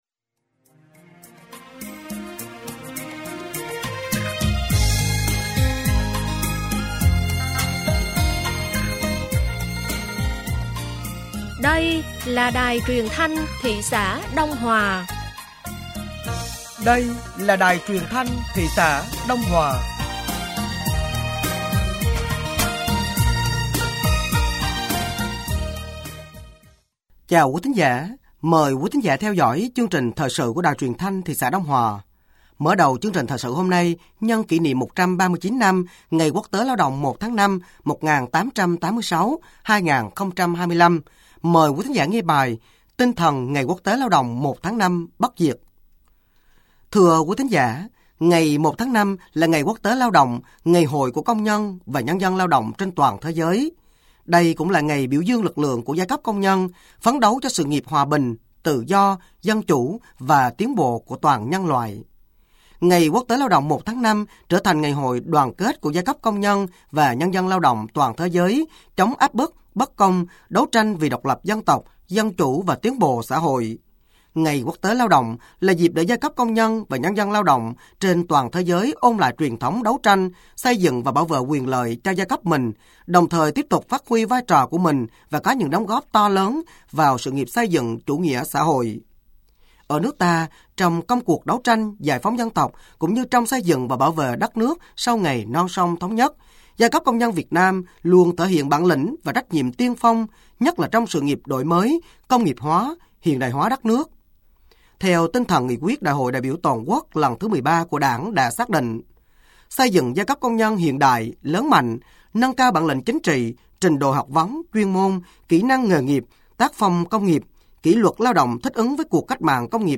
Thời sự tối ngày 01 và sáng ngày 02 tháng 5 năm 2025